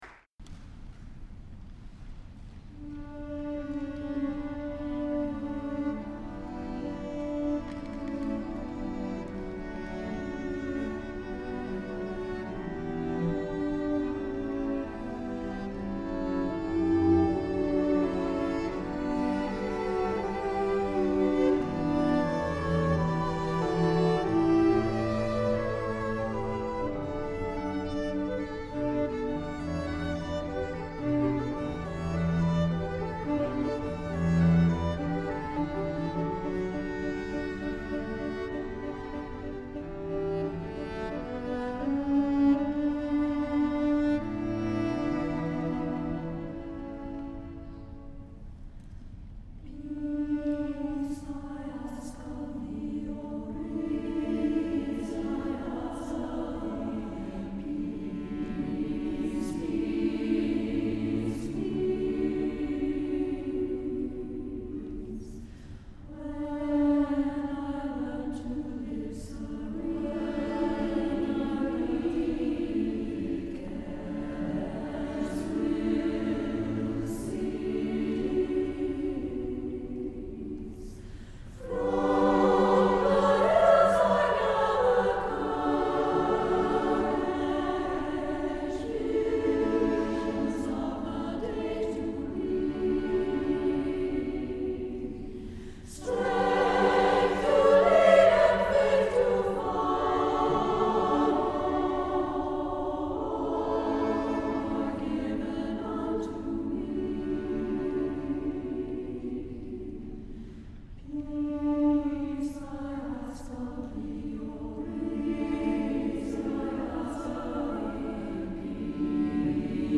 for SSAA Chorus and String Orchestra (2005)
with strings
The work opens with an ascending scale, as a supplication.